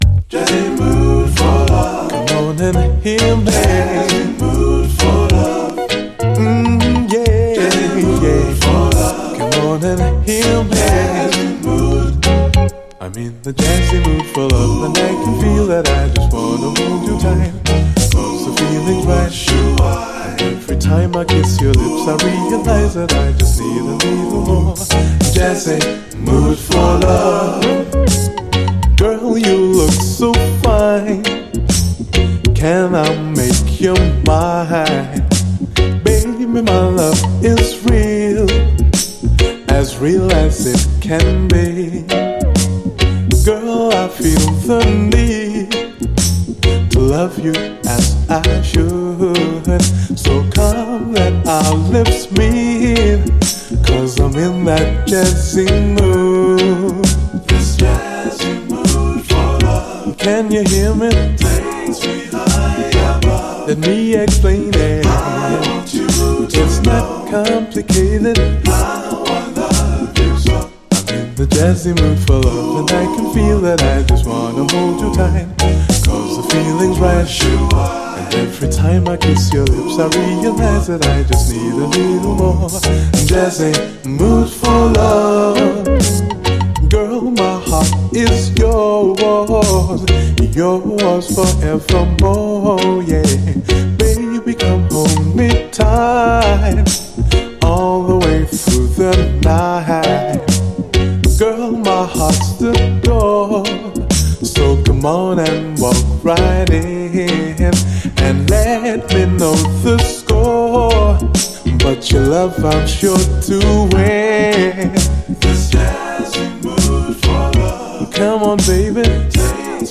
REGGAE
シュビドゥワ・コーラスとメロウ＆ジャジーな洒落たサウンドのグレイトUKラヴァーズ！
シュビドゥワ・コーラス含めライトなサウンド全開の軽やかなメロウ＆ジャジー・ラヴァーズ！